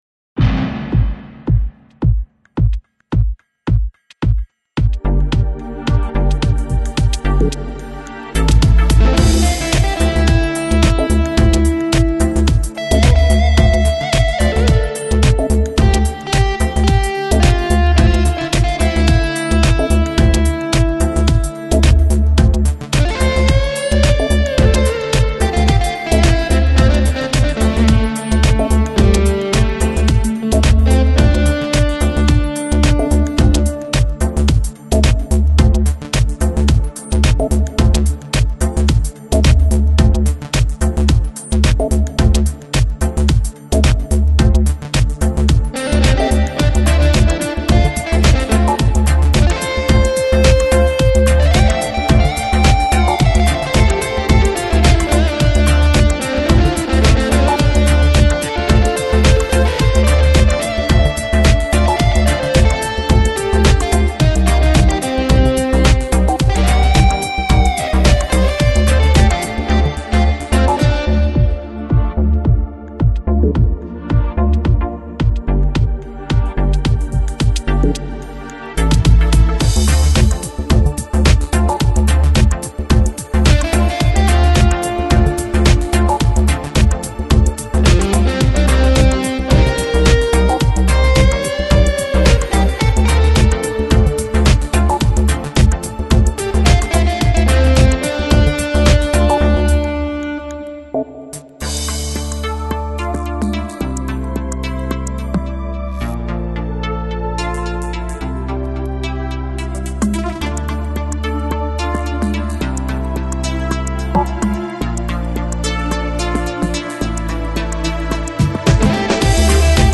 Chill Out, Downtempo